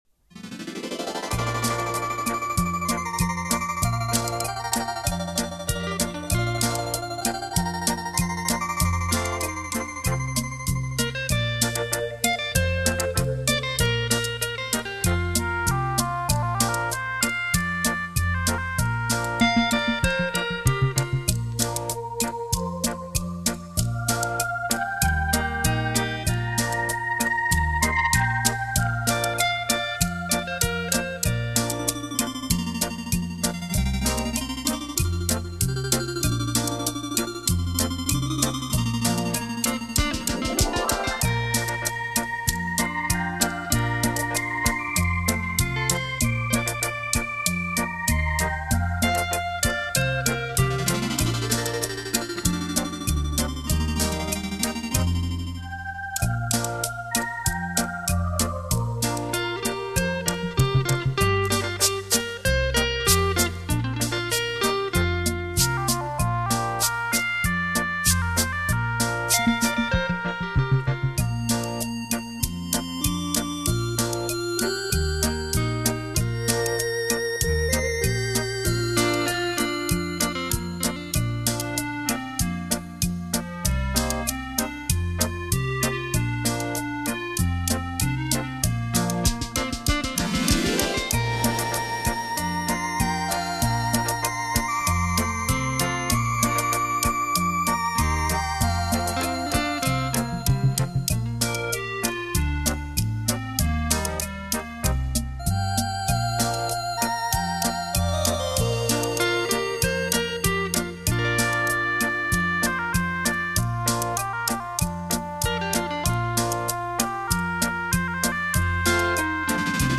行云流水的音，清澈透明的琴声。
以最通俗动听曲目表现电子风韵，
音色通透鲜明，音像玲珑浮现，发烧友不能不买。